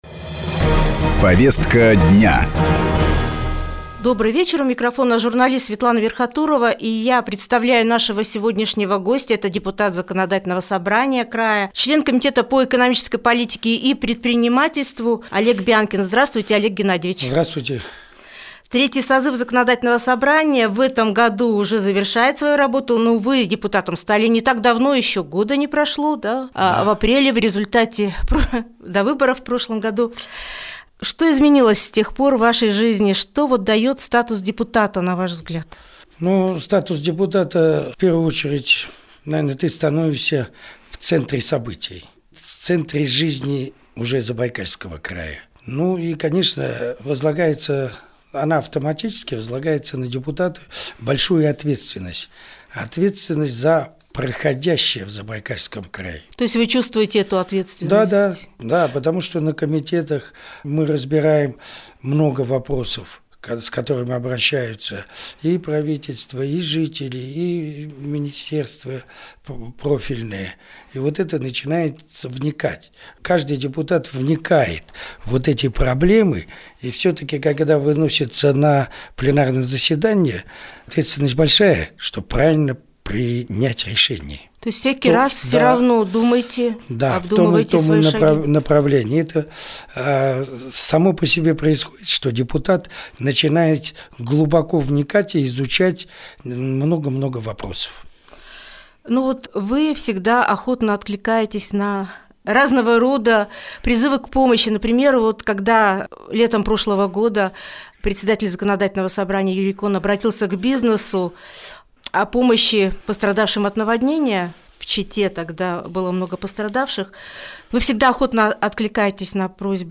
О деятельности депутатов Заксобрания Забайкалья – устами члена комитета по экономической политике и предпринимательству Олега Бянкина